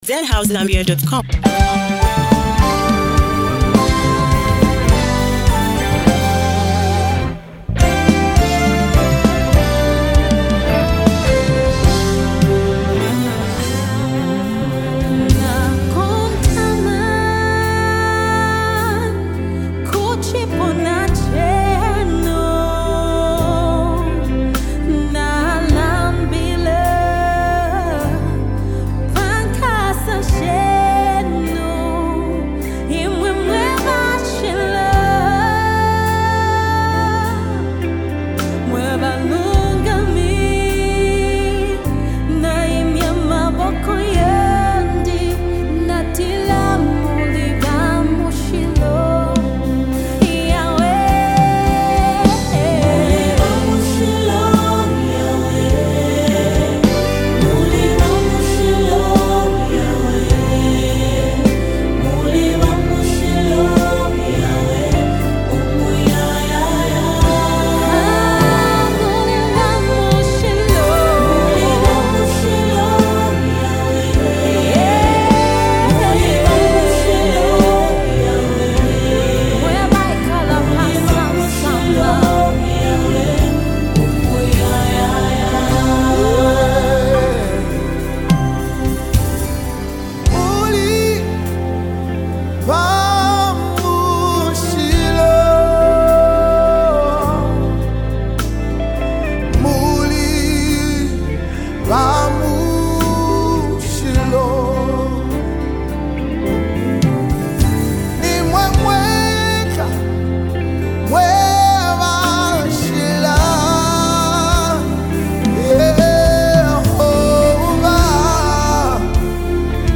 A soul-lifting gospel masterpiece